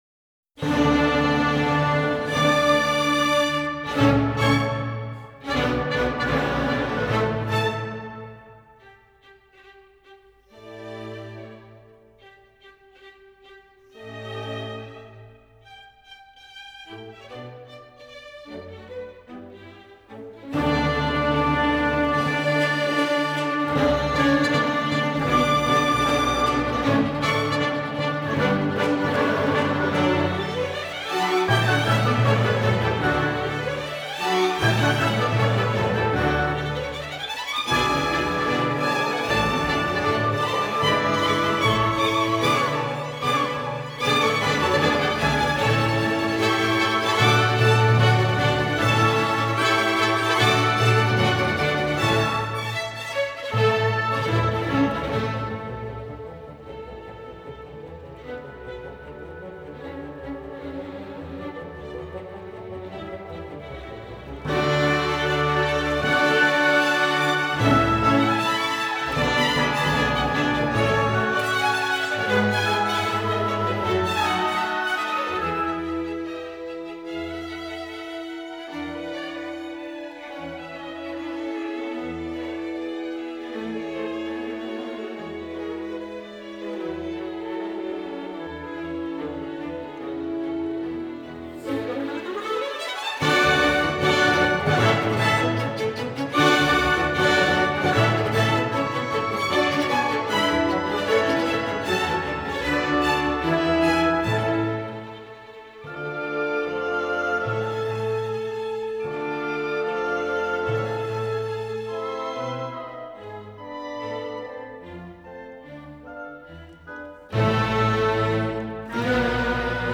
wolfgang-amadeus-mozart-symfonia-d-dur-allegro-con-spirito.mp3